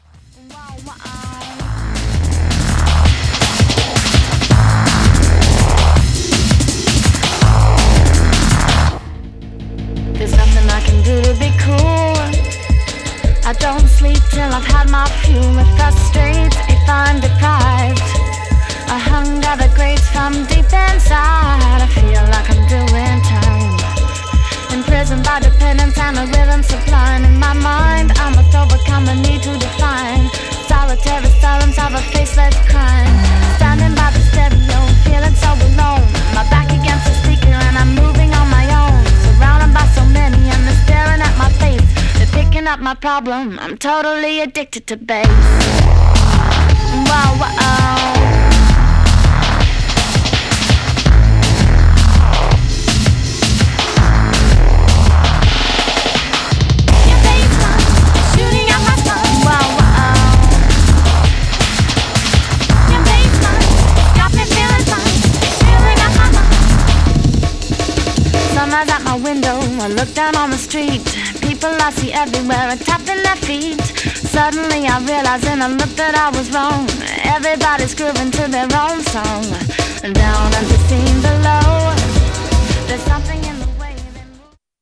Styl: Progressive, Drum'n'bass, House